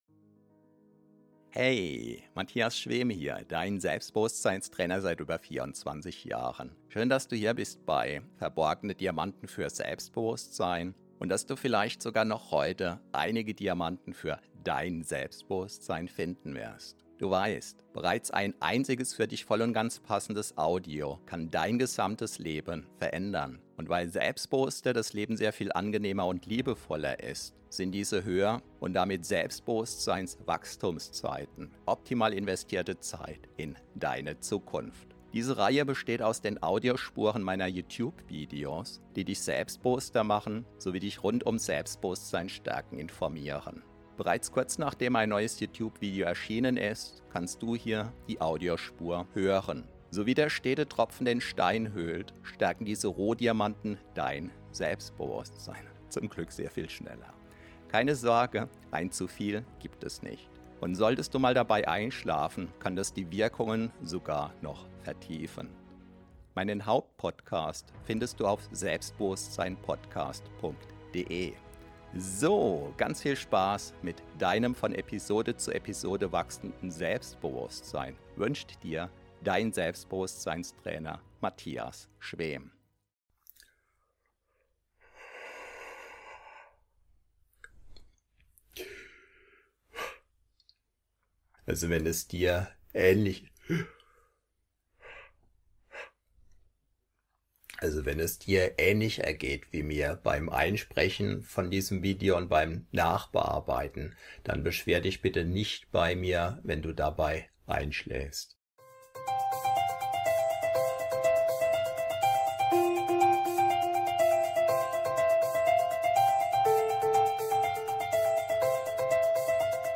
Diese Hypnose zum Einschlafen & Durchschlafen holt dich schnell ins Traumparadies: Einzigartig: Das unhörbare Ende, damit du nicht aus dem Einschlafen rausgerissen wirst.
Einschlafmusik unterlegt die Tiefschlafhypnose, die zusätzlich viele wirkungsvolle Suggestionen beinhaltet und zum Einschlafen hin leiser wird, das Ende ist garantiert unhörbar, sodass du in den Tiefschlaf hinübergleiten kannst, garantiert.